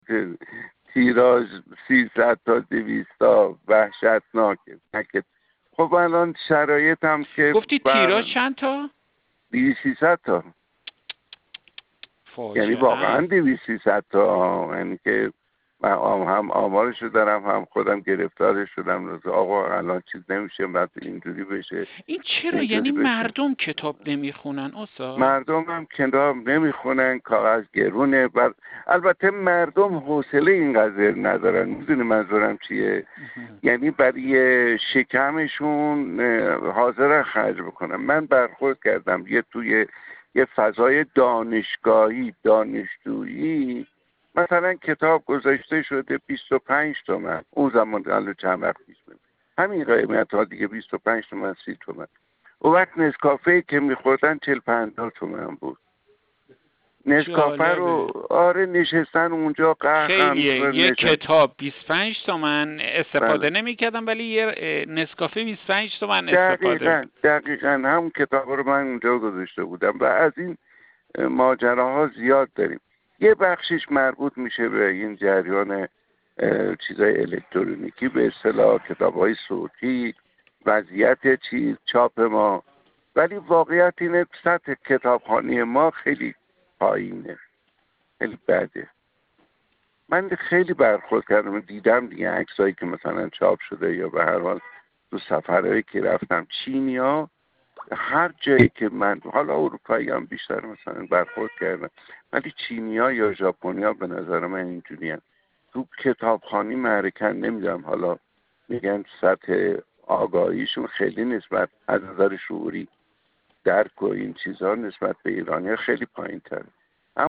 رضا فیاضی، بازیگر و داستان‌نویس، در گفت‌وگو با ایکنا، با بیان اینکه کتابخوانی در کشورمان به فرهنگی فراموش‌شده است، گفت: تیراژ کتاب در کشورمان به پایین‌ترین حد خود رسیده است، به‌نحوی‌که برخی کتاب‌ها با تیراژ 200 عدد به چاپ می‌رسد!